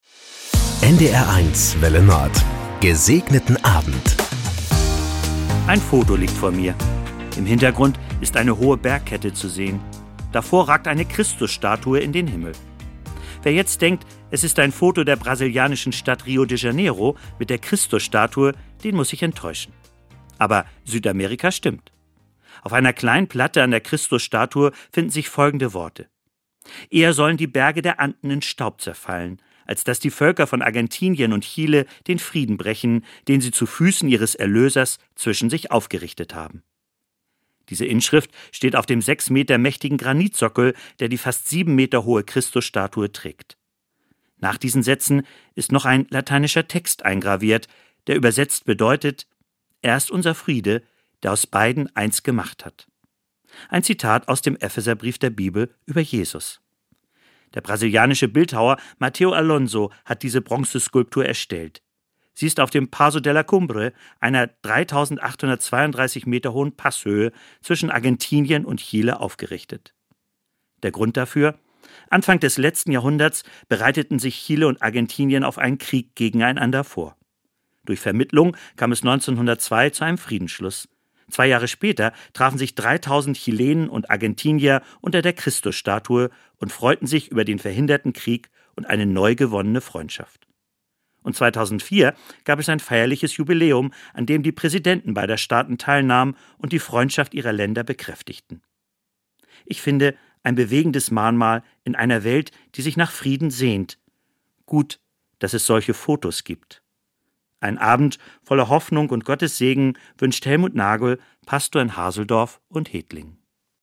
Das gute Wort zum Feierabend auf NDR 1 Welle Nord mit den Wünschen für einen "Gesegneten Abend". Von Sylt oder Tönning, Kiel oder Amrum kommt die Andacht als harmonischer Tagesabschluss.